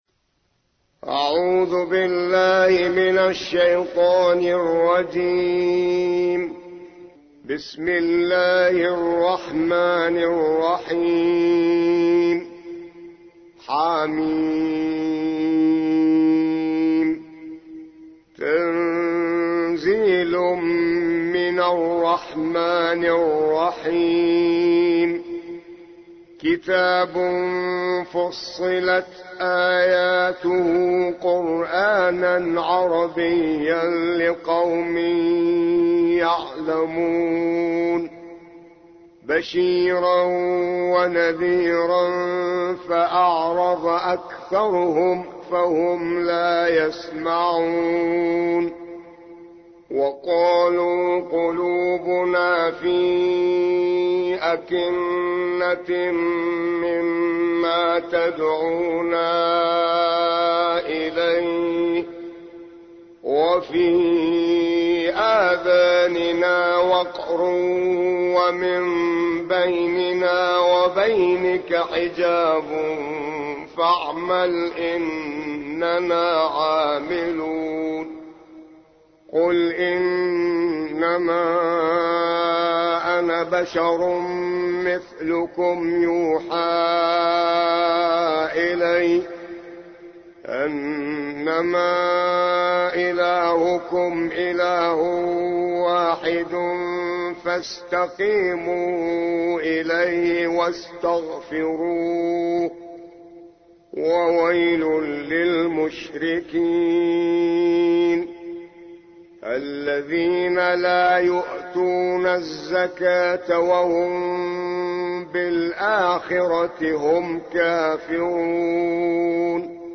41. سورة فصلت / القارئ